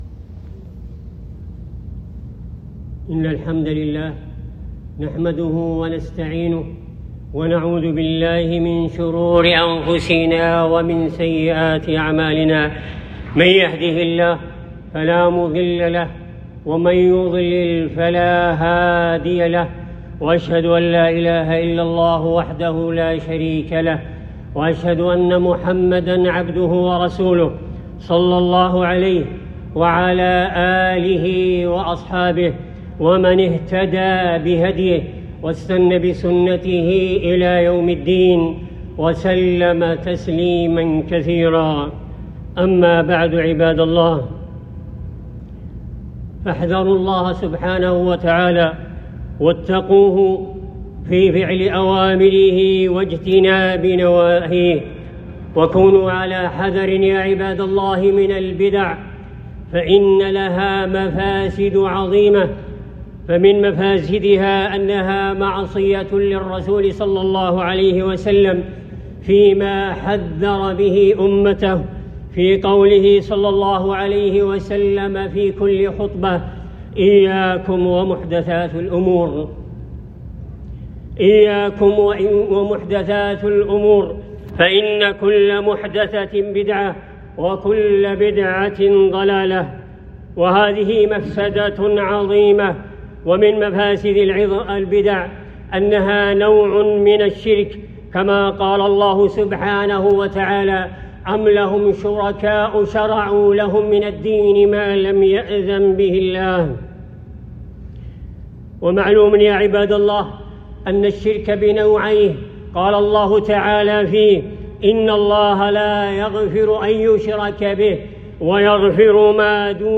التحذير من البدع وتحذير من بدعة المولد - ألقيت بمكة يوم الجمعة 13ربيع الأول 1442